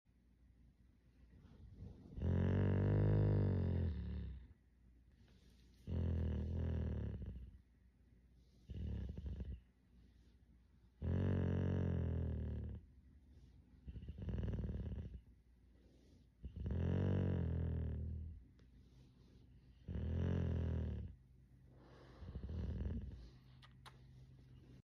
A purring German Shepherd ❤🐾 sound effects free download